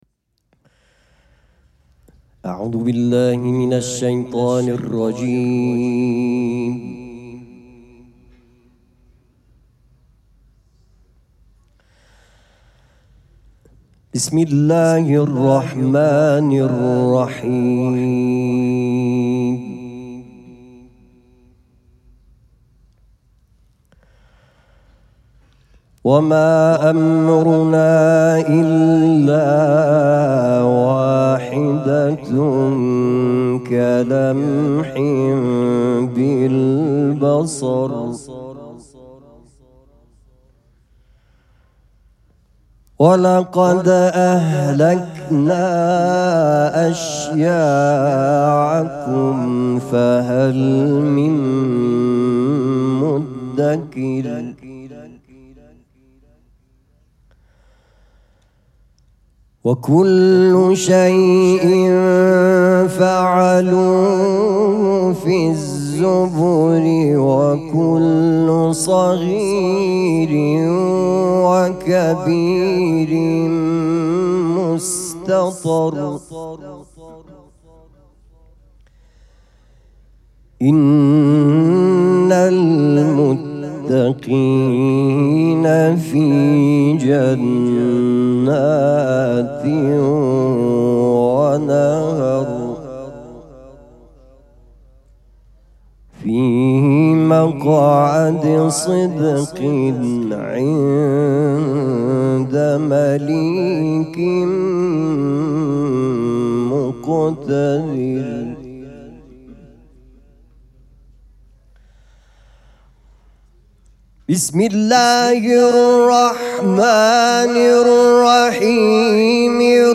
شب پنجم مراسم عزاداری دهه دوم فاطمیه ۱۴۴۶
قرائت قرآن